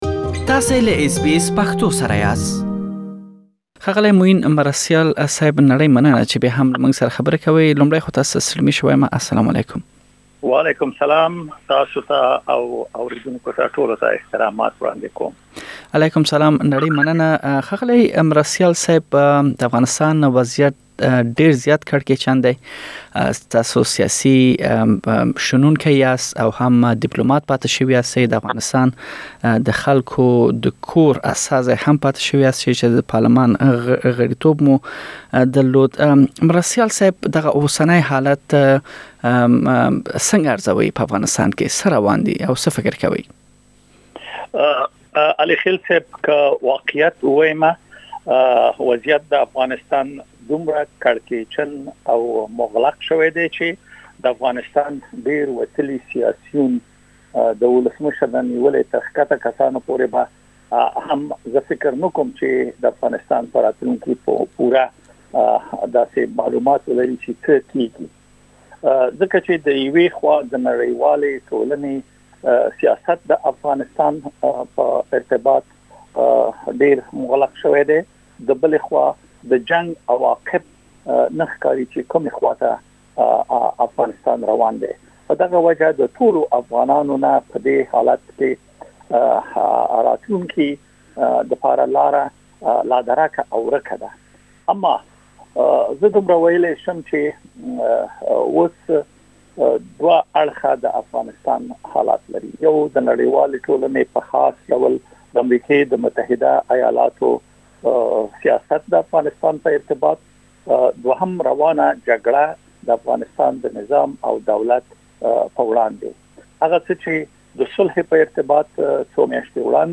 سياسي چارو شنونکی او د افغانستان د پارلمان پخوانی غړی معين مرستيال له اس بي اس پښتو خپرونې سره خبرو پر مهال وويل، افغانستان کې د سولې خبرې اوس په جګړې خبرو بدلې شوي، چې دا خپل د شا تګ نښه ده.
تاسو ته مو د افغانستان روان حالت ژور څيړلی، دا او نور مهم معلومات پدې مرکه کې اوريدلی شئ.